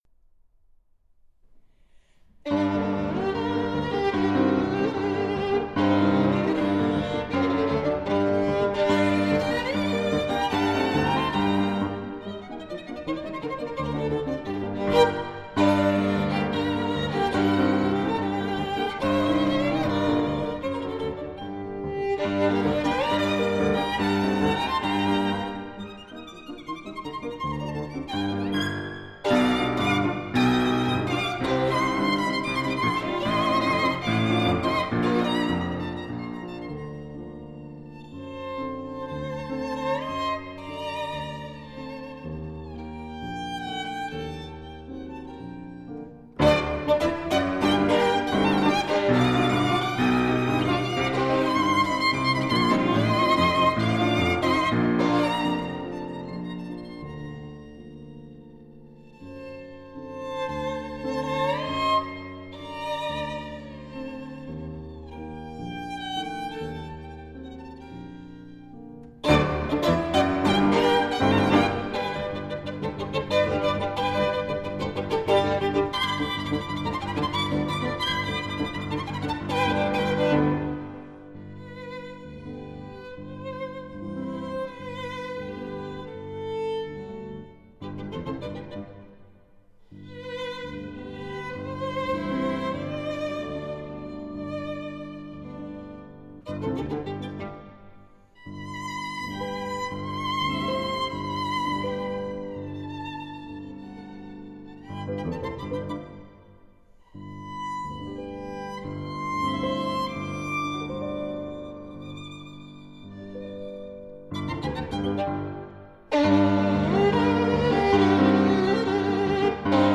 钢 琴
in G minor